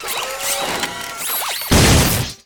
robot punch.ogg